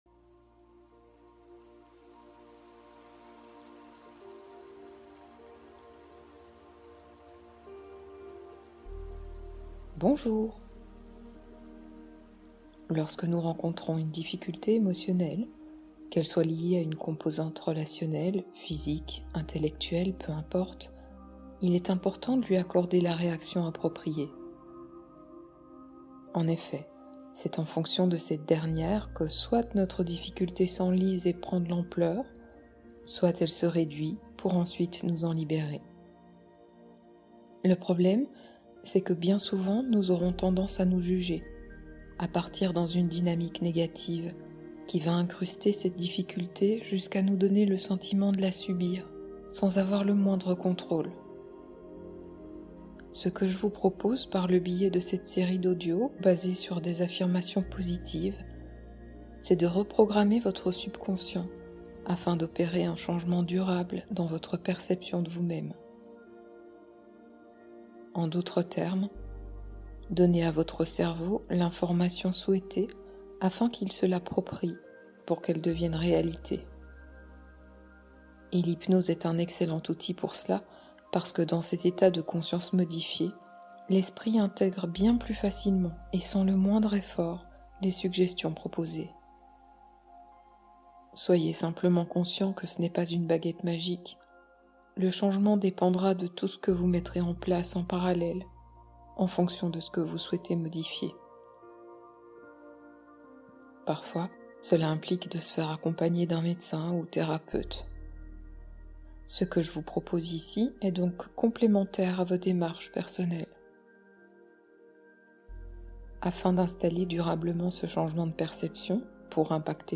De la mer au sommeil : immersion hypnotique apaisante